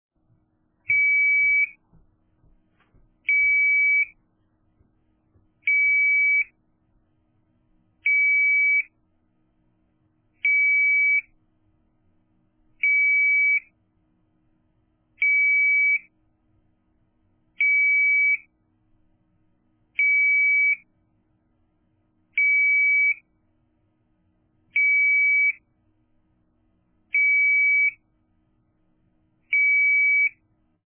After this delay, it emits a loud pulsing tone to alert you to the fact that the door has been left open.
Using a set of dip switches, the user can select the delay time, they can turn the initial chirp on or off, they can turn the LED on or off, they can choose between the pulsing beep or a steady tone, and can even turn the buzzer off (using the LED as a signal that the door is open if desired).
With it's sound pressure level of 85db at 10cm, it can be heard throughout a typical 3 story home or small office building.
DoorAlarm-01.mp3